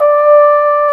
Index of /90_sSampleCDs/Roland L-CDX-03 Disk 2/BRS_Cornet/BRS_Cornet 2